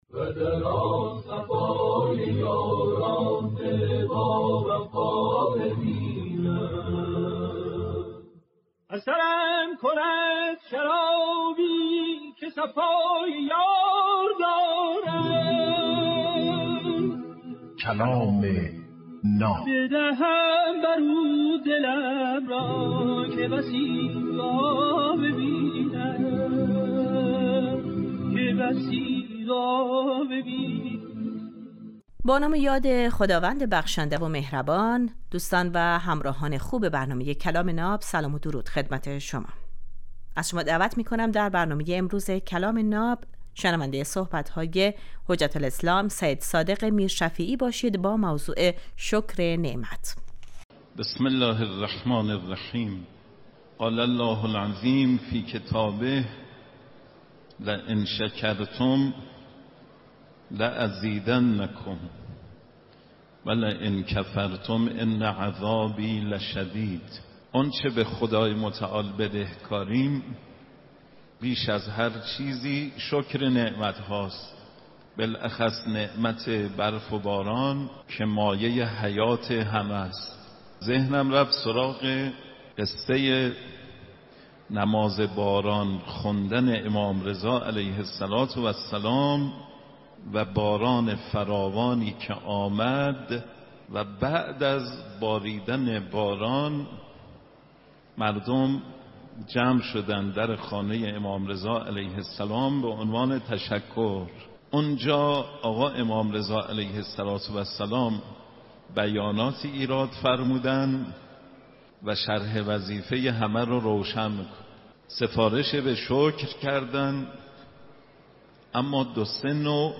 در این برنامه هر روز یک سخنرانی آموزنده کوتاه پخش می شود. این برنامه هر روز صبح از رادیو دری پخش میشود و در پایان برنامه های رادیو دری در چخش نیمروزی تکرار آن را خواهید شنید.